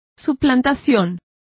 Complete with pronunciation of the translation of impersonation.